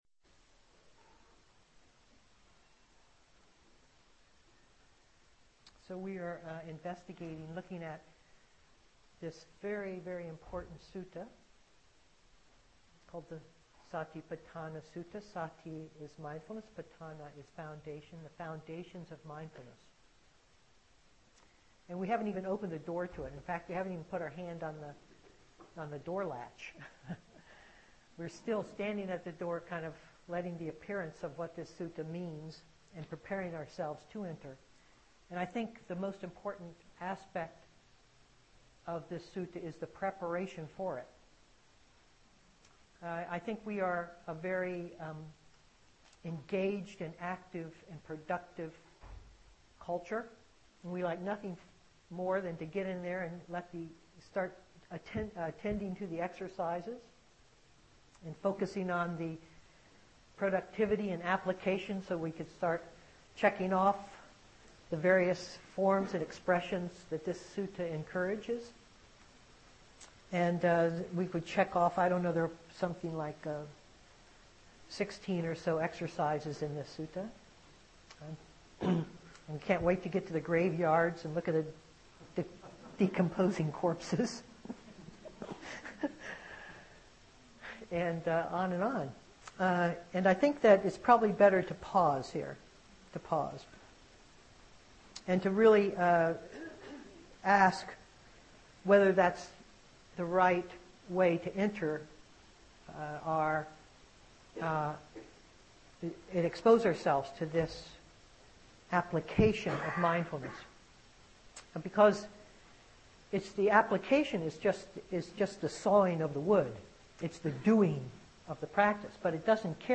Venue: Seattle Insight Meditation Center